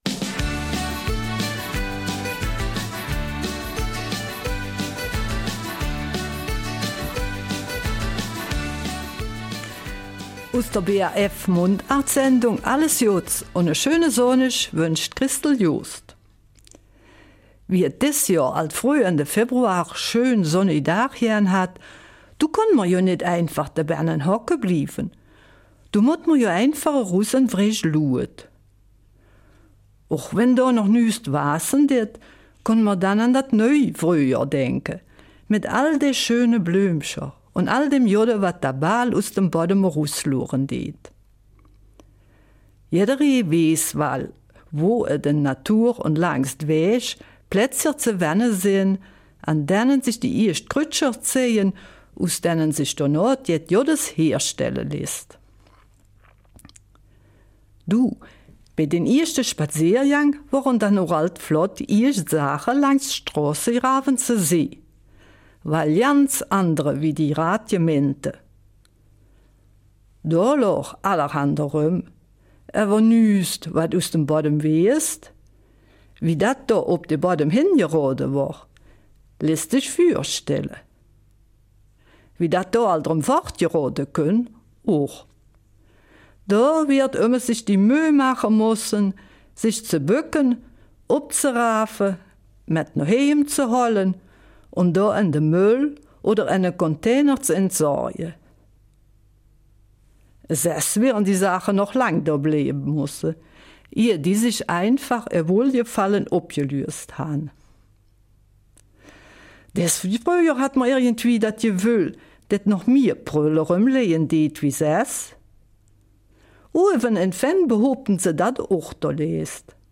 Eifeler Mundart: Delikatessen vom Wegesrand